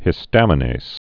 (hĭ-stămə-nās, -nāz, hĭstə-mə-)